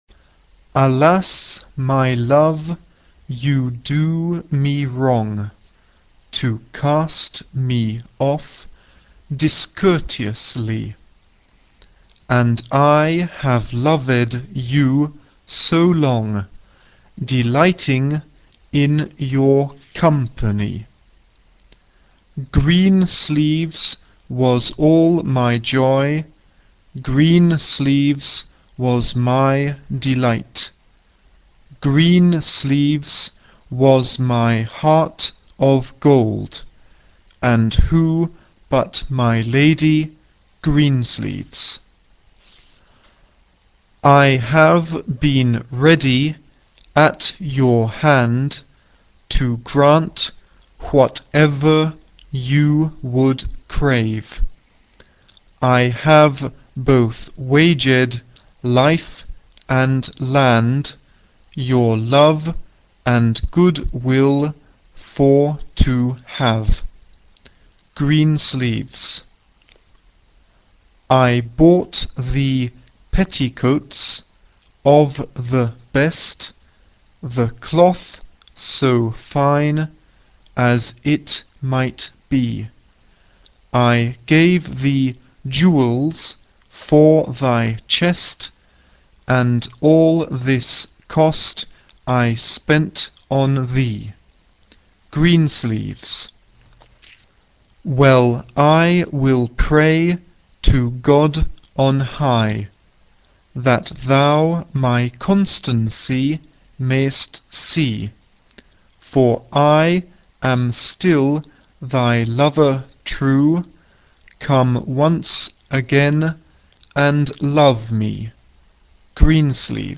SAB (3 voix mixtes) ; Partition choeur et accords.
Folklore. Chanson.
Tonalité : mi mineur